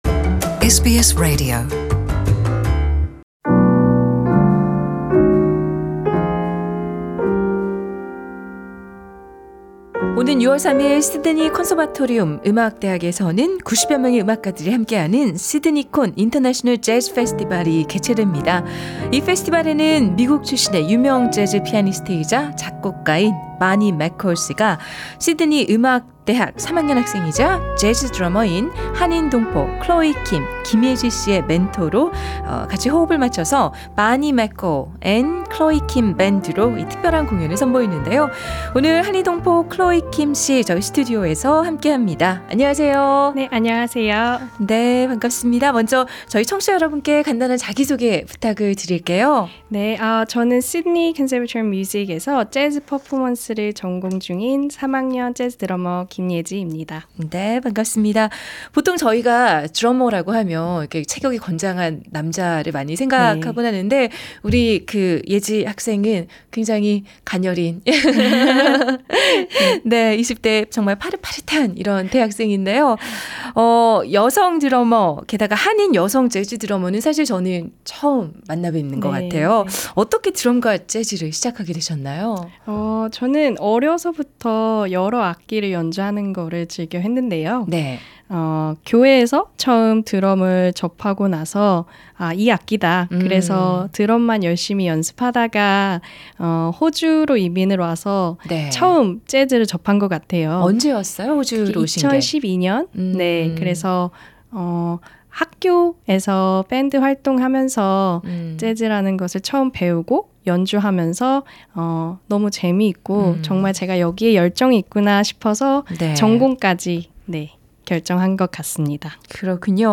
The full interview is available on the podcast above.